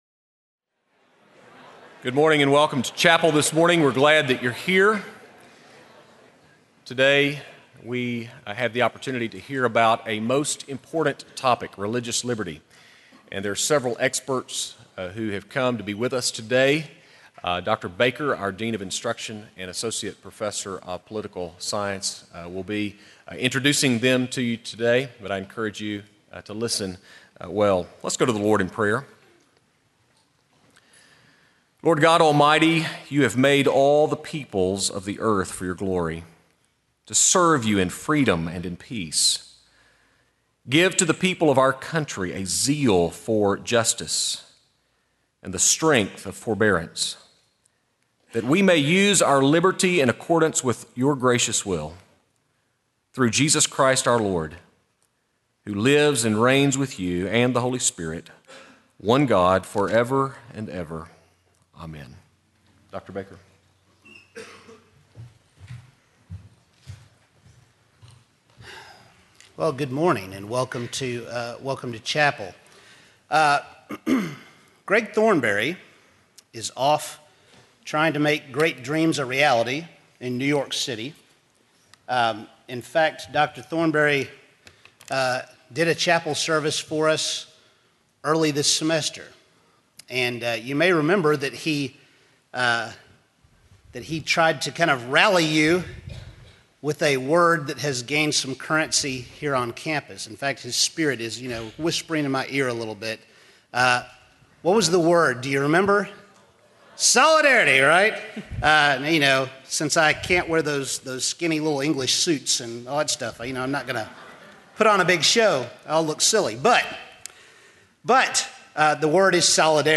Chapel: Panel on Religious Liberty